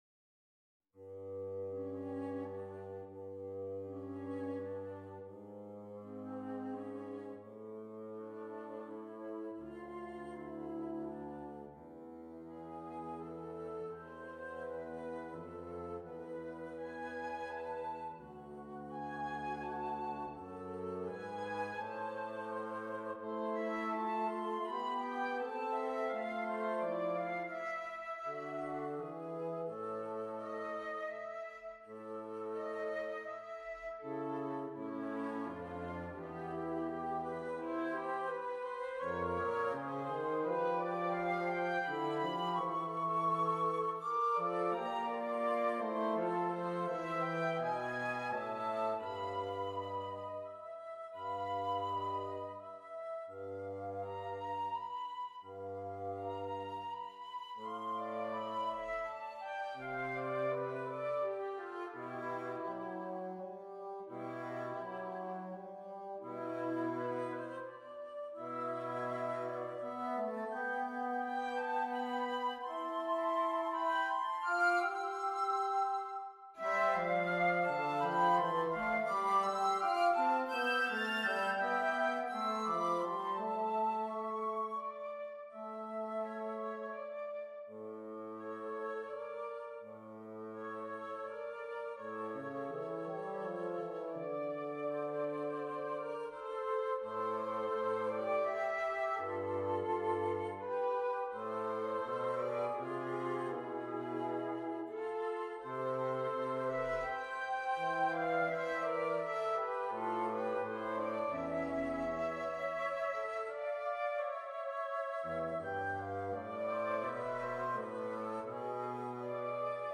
for flute, alto flute, & bassoon